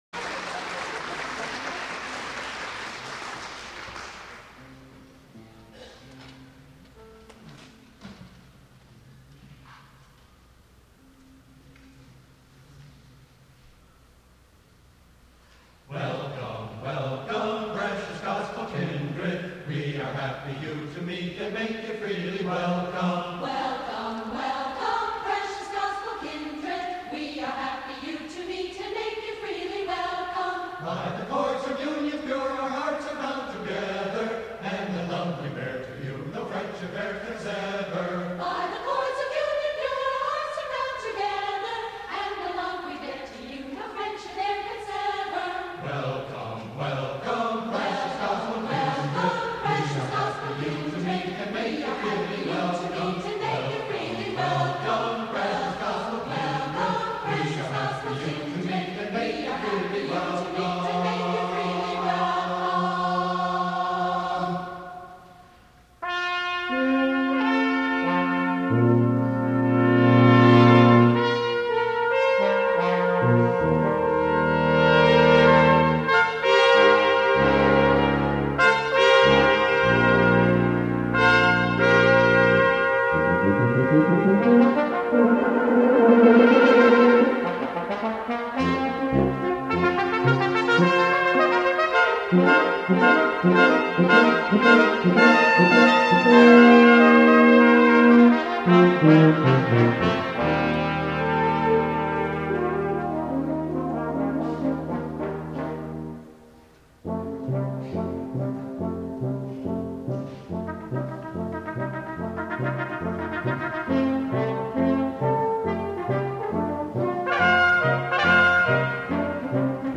for Brass Quintet (1994)
with singing